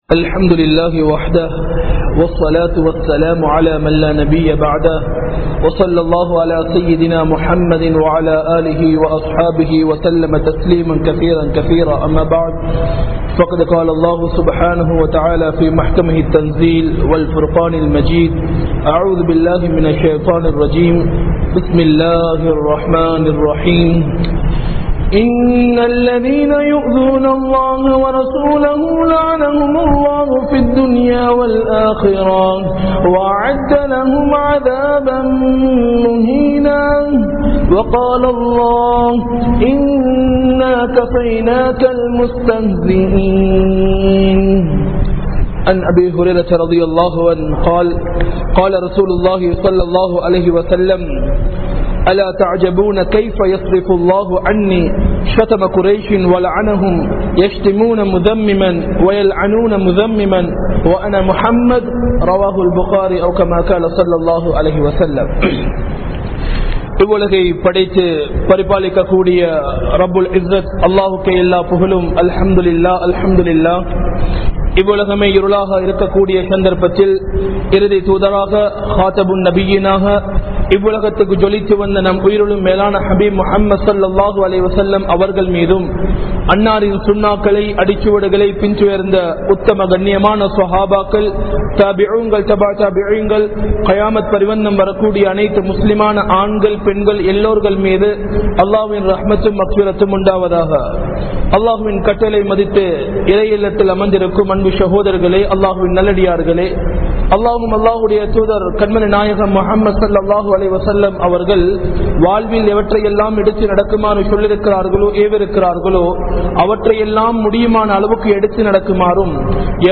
Nabiyavarhalai Vimarsiththavarhalin Iruthi Nilai (நபியவர்களை விமர்சித்தவர்களின் இறுதி நிலை) | Audio Bayans | All Ceylon Muslim Youth Community | Addalaichenai
Colombo 04, Majma Ul Khairah Jumua Masjith (Nimal Road)